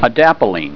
Pronunciation
(a DAP a leen)